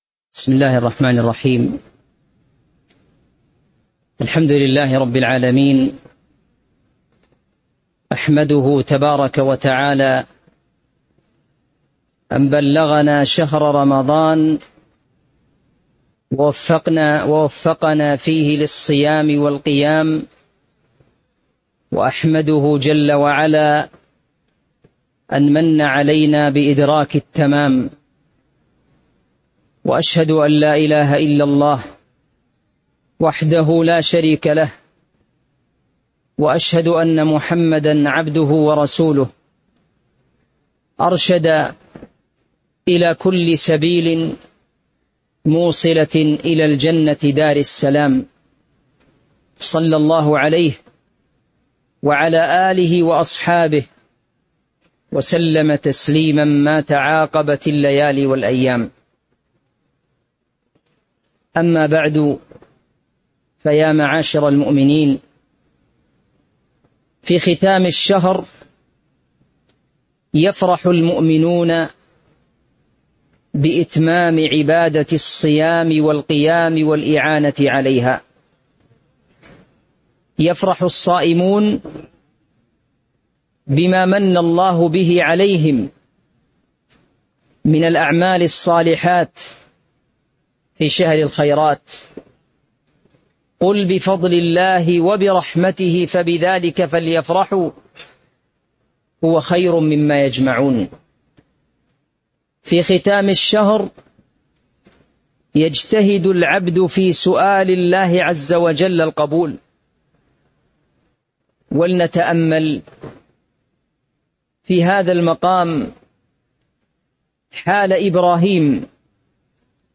تاريخ النشر ٢٨ رمضان ١٤٤٥ هـ المكان: المسجد النبوي الشيخ